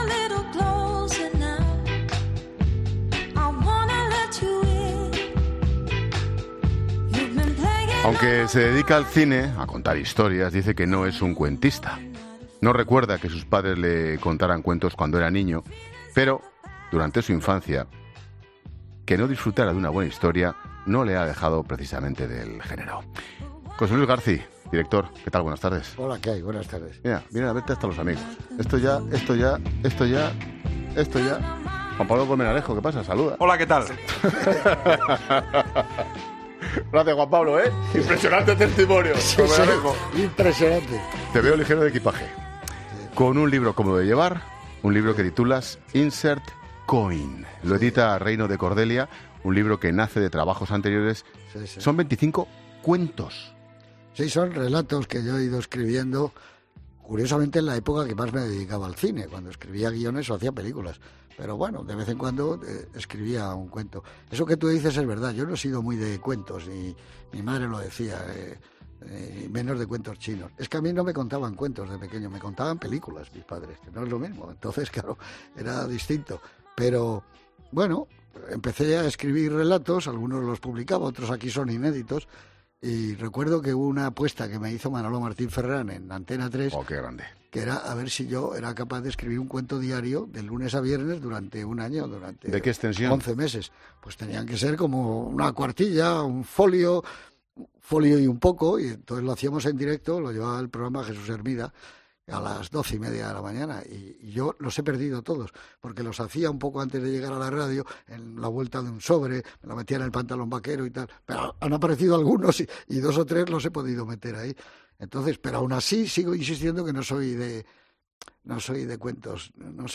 Ángel Expósito entrevista a José Luis Garci, director de cine y autor de Insert Coin